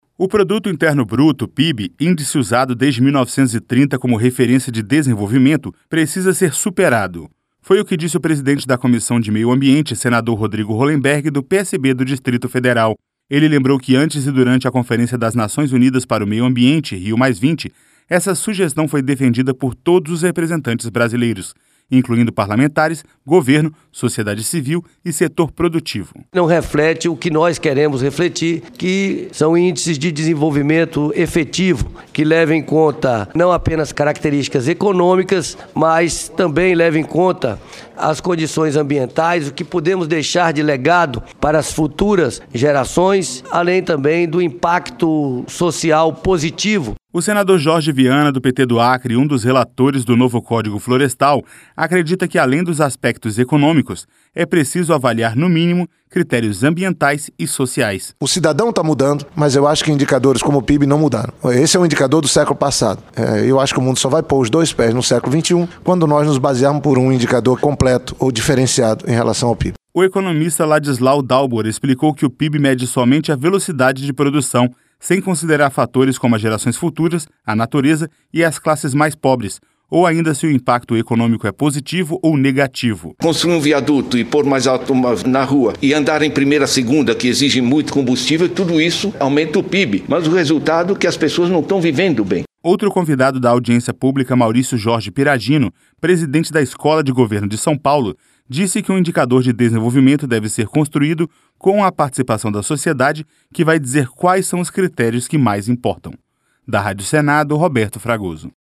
LOC: O DESENVOLVIMENTO DE UM PAÍS DEVE SER MEDIDO POR UM CONJUNTO DE INDICADORES ALÉM DA ECONOMIA; COMO QUALIDADE DE VIDA, PROTEÇÃO AMBIENTAL E DISTRIBUIÇÃO DE RENDA. LOC: FOI O QUE DEFENDERAM ESPECIALISTAS EM AUDIÊNCIA PÚBLICA NA COMISSÃO DE MEIO AMBIENTE DO SENADO NESTA TERÇA-FEIRA.